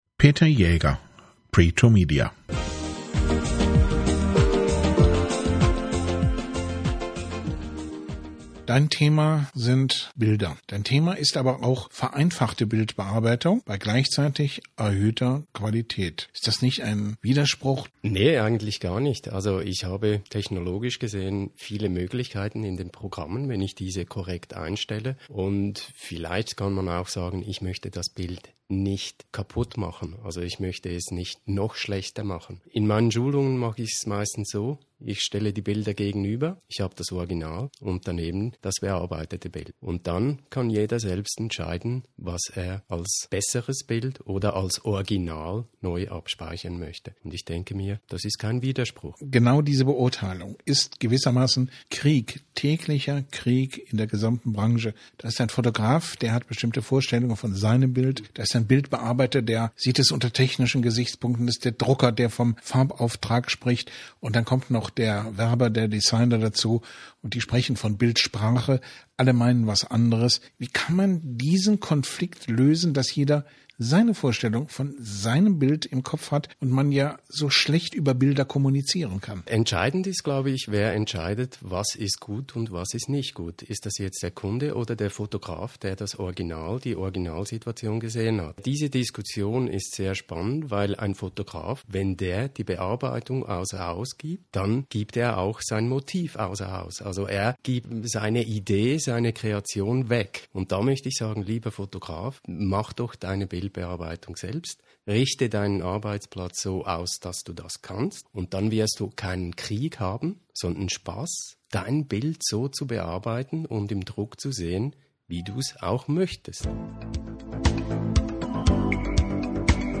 Interview, ca. 6 Minuten